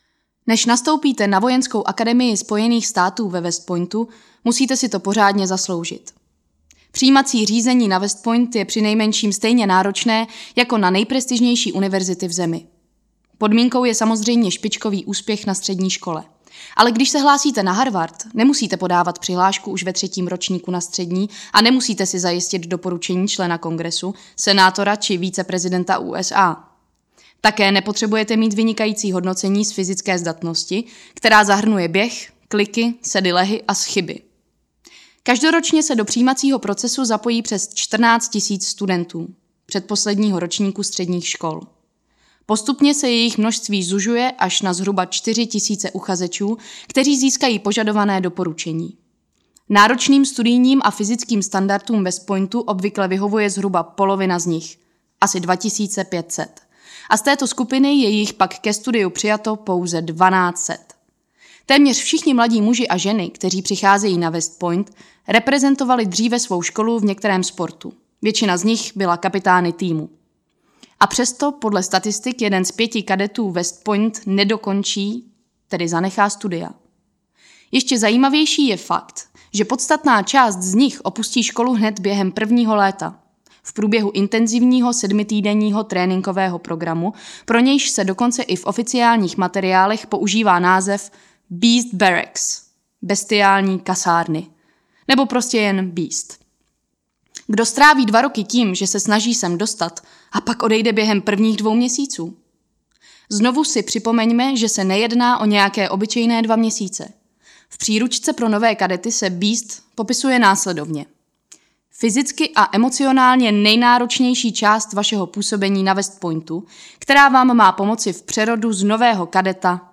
Houževnatost audiokniha
Ukázka z knihy